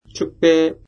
韓国人講師の音声を繰り返して聞きながら発音を覚えましょう。
発音と読み方
축배 [チュクベ]